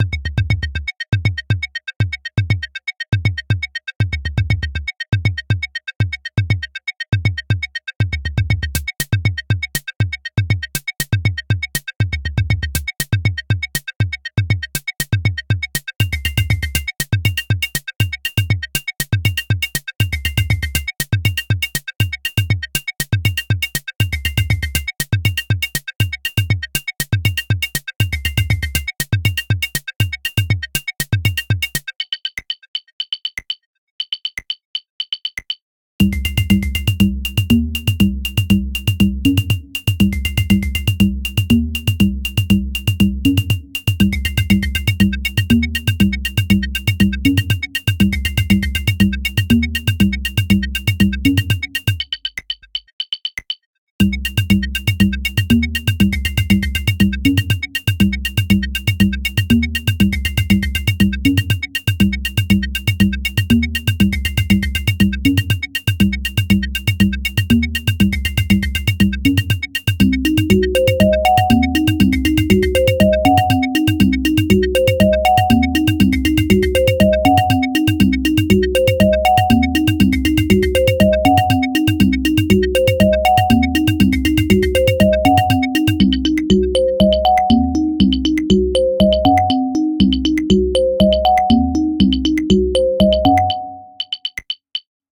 Pieza Intelligent dance music (IDM)
Música electrónica
Dance